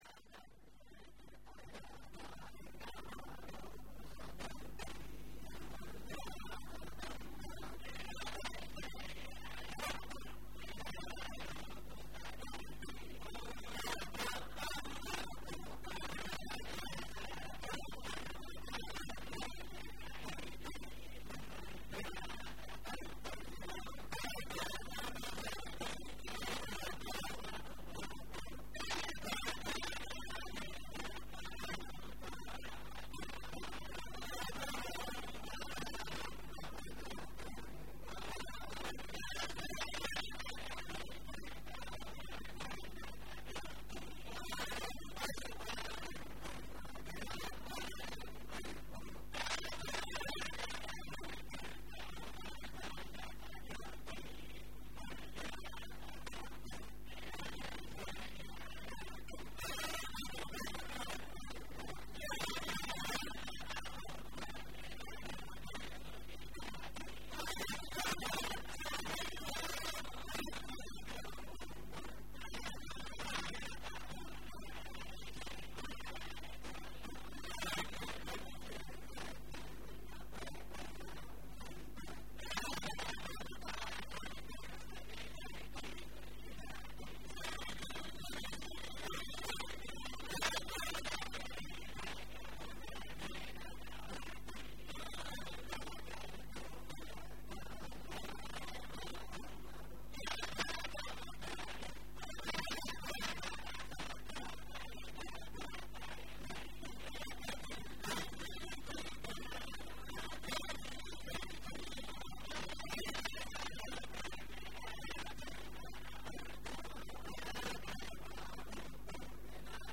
Wakokin Gargajiya